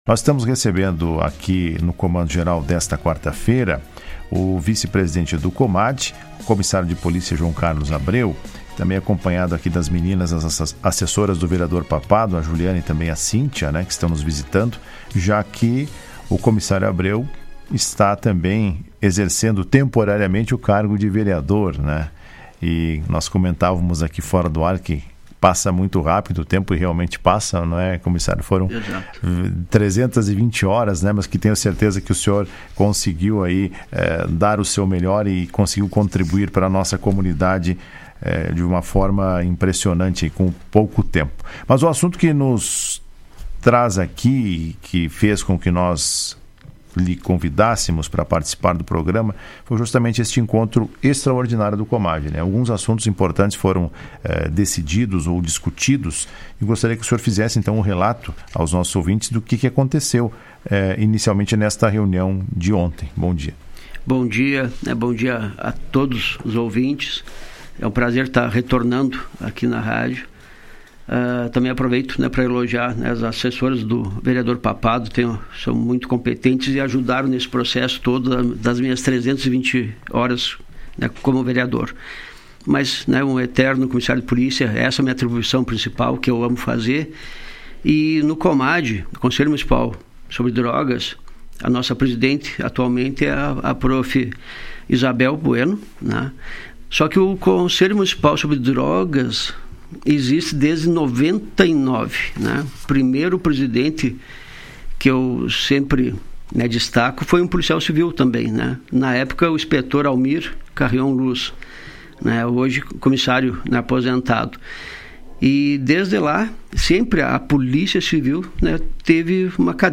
Durante a entrevista ele fez um relato dos assuntos discutidos reunião extraordinária da entidade que ocorreu na terça.